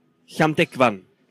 Llandegfan (Welsh: [ɬan'dɛɡvan];
Welsh pronunciation; meaning The Church of St Tegfan) is a village on the east of island of Anglesey in Wales.